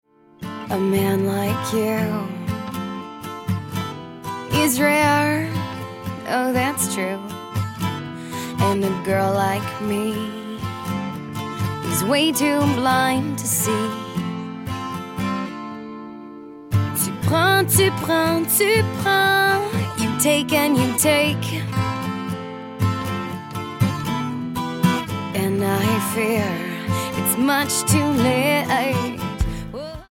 Alternative,Folk,Rock